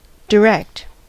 Ääntäminen
US : IPA : [də.ˈɹɛkt]